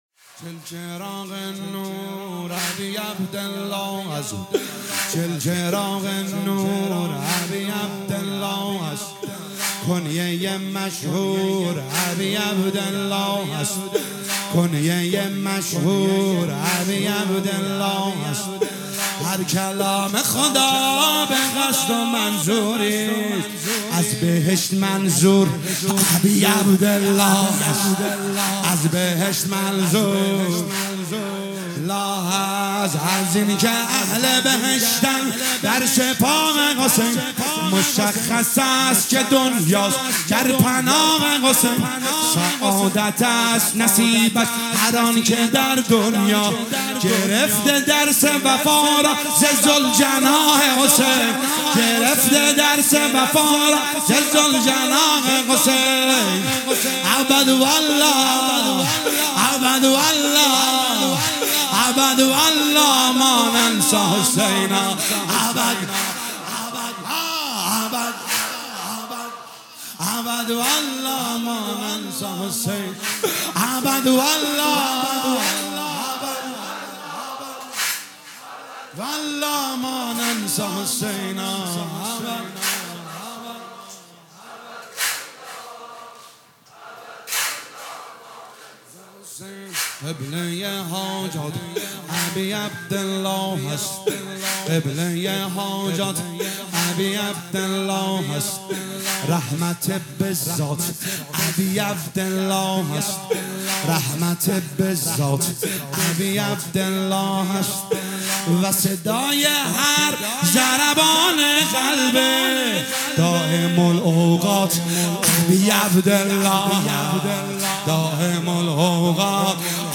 مداحی واحد شب چهارم محرم 1445